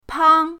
pang1.mp3